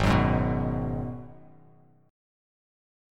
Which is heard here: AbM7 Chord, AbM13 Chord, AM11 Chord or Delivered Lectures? AbM7 Chord